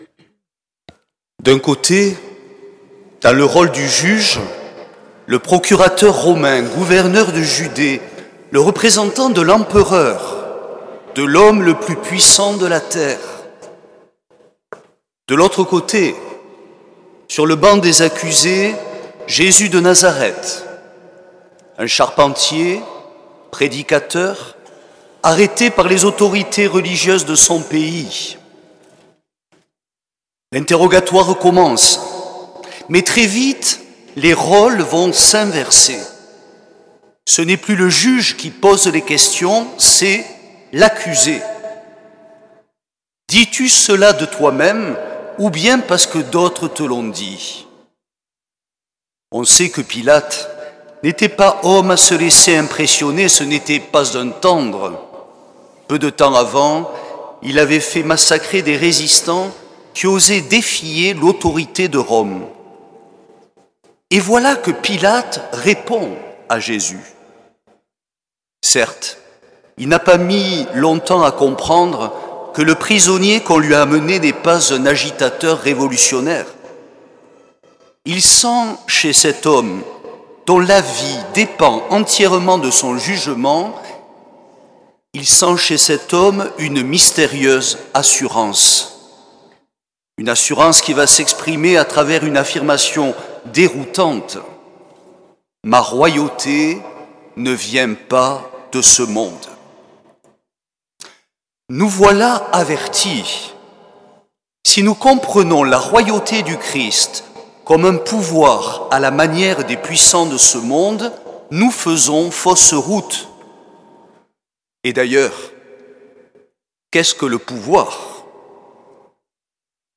L’enregistrement retransmet l’homélie